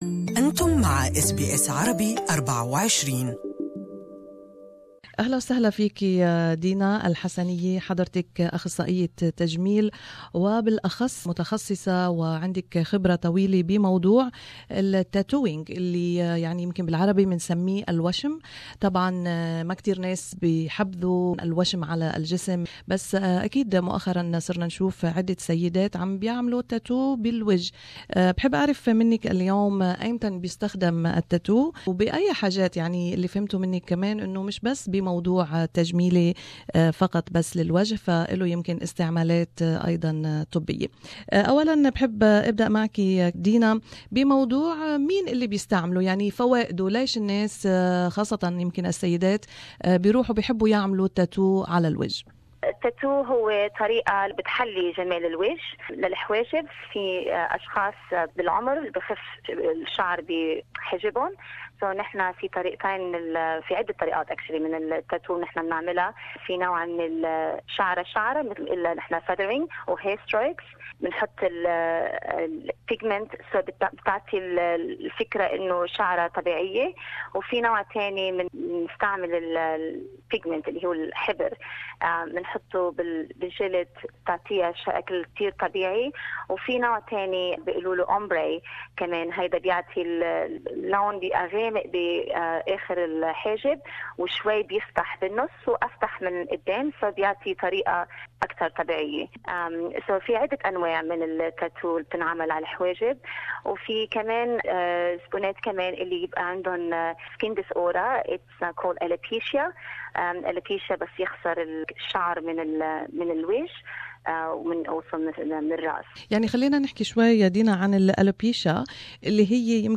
أخصائية التجميل